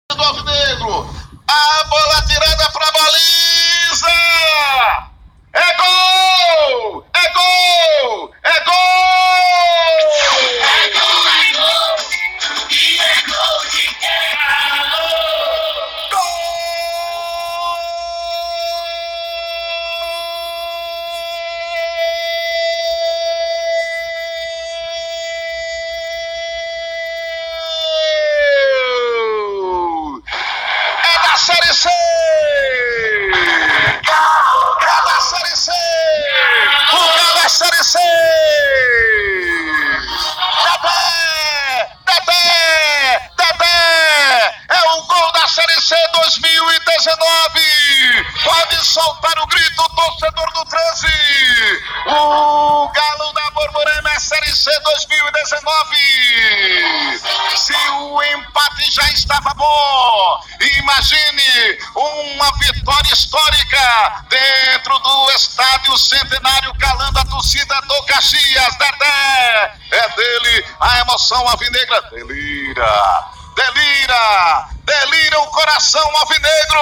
Depois disso, a torcida do Caxias e invadiu o campo de jogo e passou a praticar violência contra jogadores do Treze. Ouça a emoção no gol da vitória do Galo da Borborema…